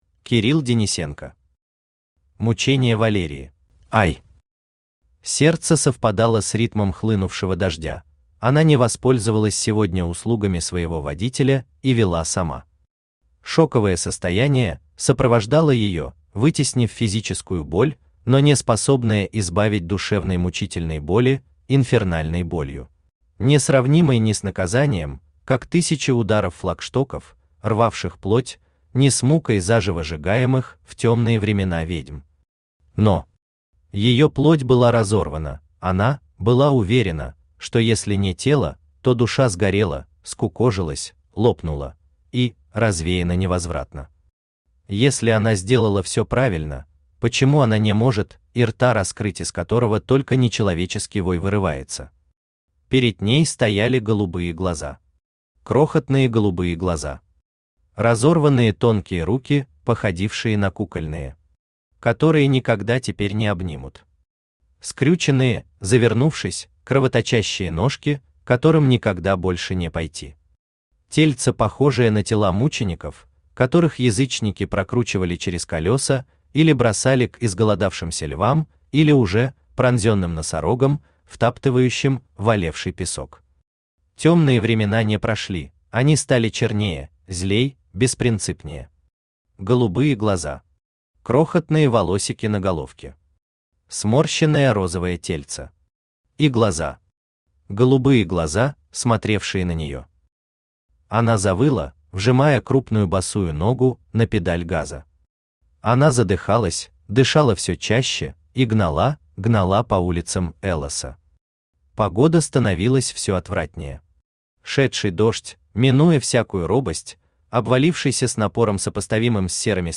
Аудиокнига Мучение Валерии | Библиотека аудиокниг
Aудиокнига Мучение Валерии Автор Кирилл Денисенко Читает аудиокнигу Авточтец ЛитРес.